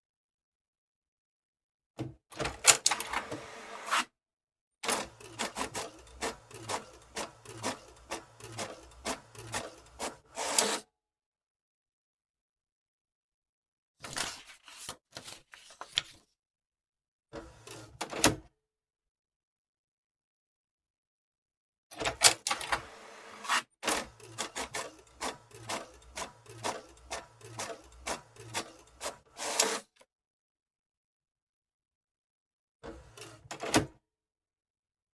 其他 " 打印机
描述：打印机打印一页纸。用墨水。
Tag: 打印 油墨 印刷 纸张 办公用品 打印机